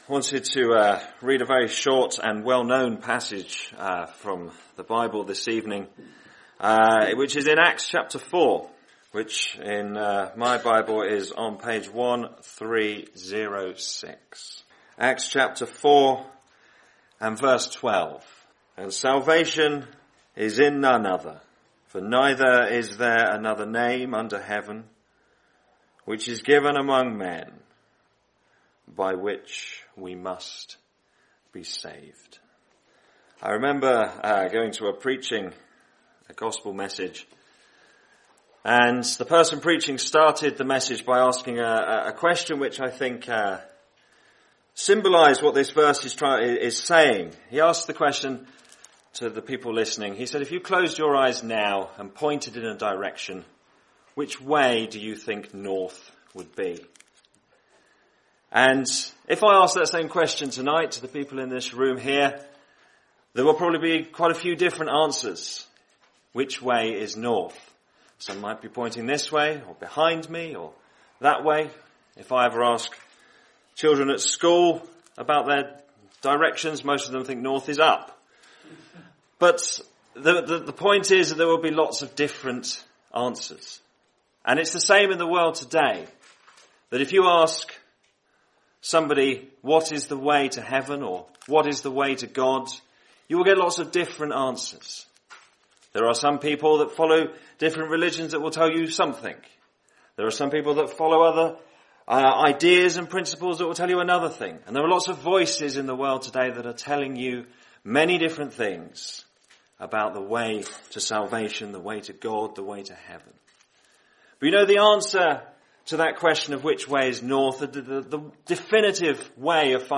The Gospel is preached to announce God's wonderful salvation plan centre in the person of the Lord Jesus Christ. God's word points us to Jesus the Saviour of sinners.